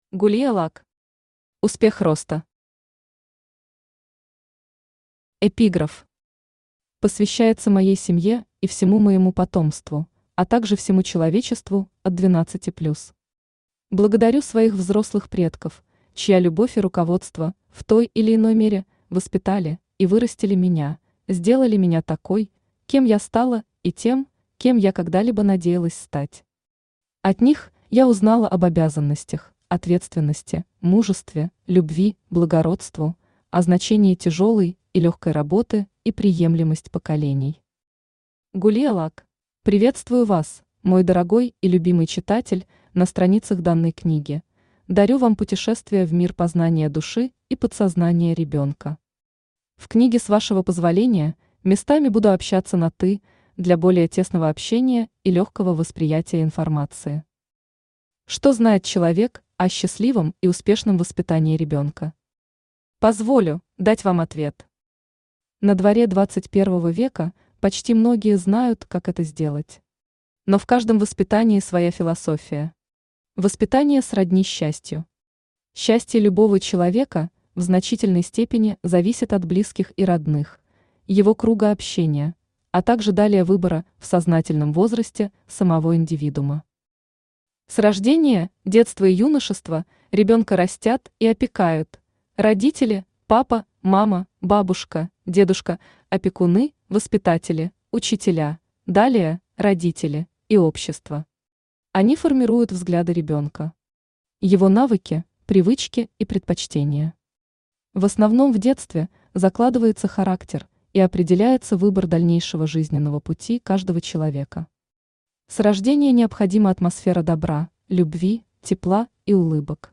Автор Gulia Luck Читает аудиокнигу Авточтец ЛитРес.